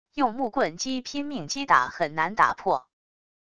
用木棍击拼命击打很难打破wav音频